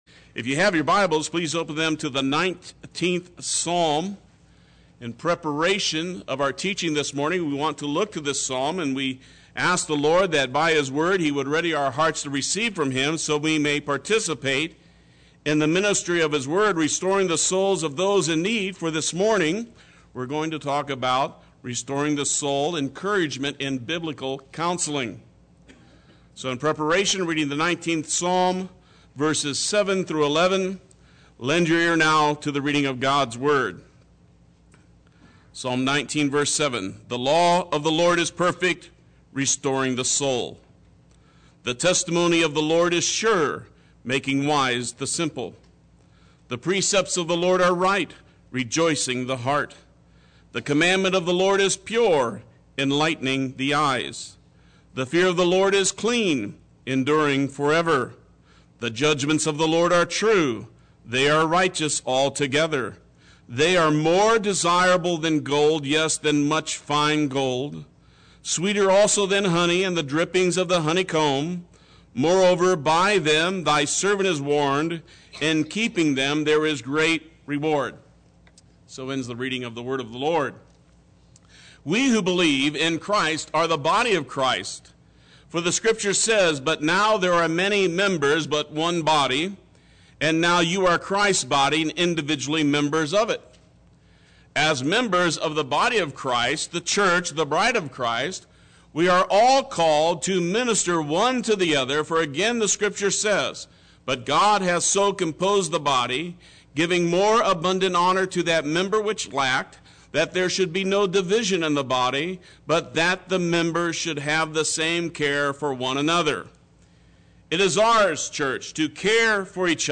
Play Sermon Get HCF Teaching Automatically.
Restoring the Soul—Encouragement in Biblical Counseling Sunday Worship